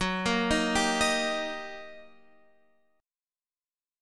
Eb/Gb chord